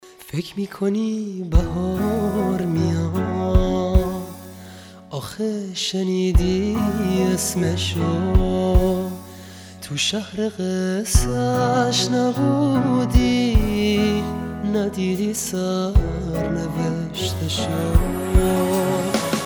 زنگ موبایل باکلام و رمانتیک